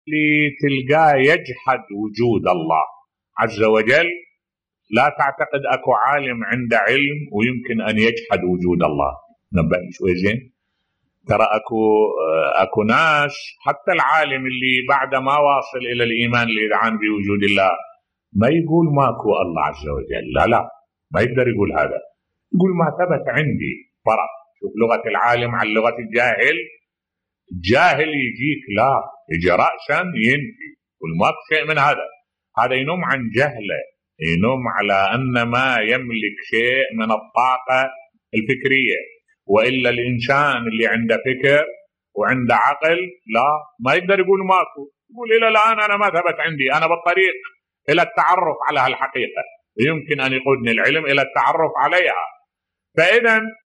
ملف صوتی لا يوجد عالم ينكر وجود الله بصوت الشيخ الدكتور أحمد الوائلي